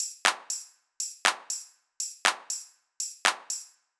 ITA Beat - Perc Mix 2.wav